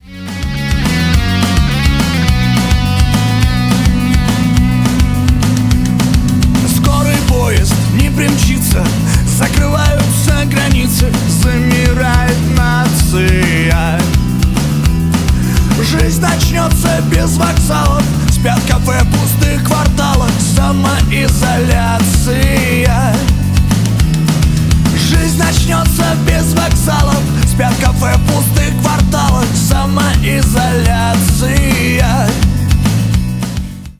• Качество: 320 kbps, Stereo
Рок Металл
весёлые
кавер